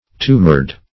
tumored.mp3